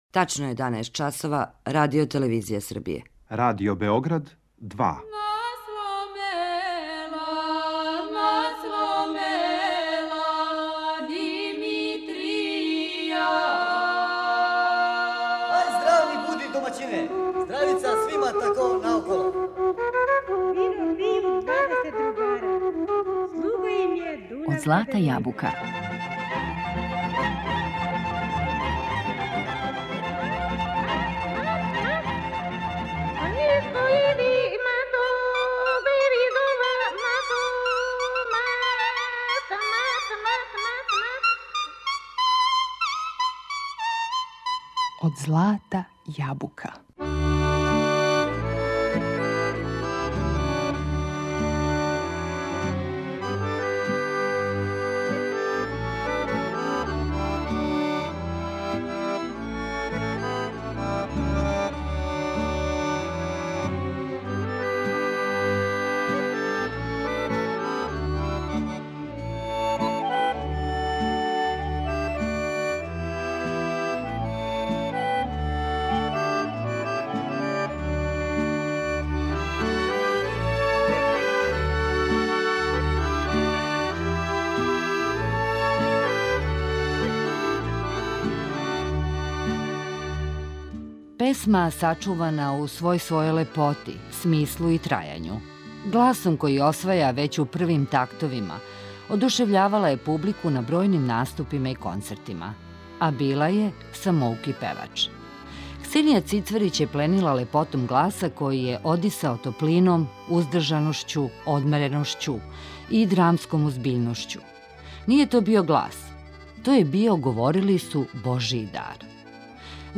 Гласом који осваја већ у првим тактовима одушевљавала је публику на бројним наступима и концертима.
Будио је емоције које изазива само савршено отпевана мелодија.
С много љубави и доследности градила је мелодију, украшавајући је елегантно и грациозно. Све песме отпеване су неком савршеном мирноћом и особеним стилом.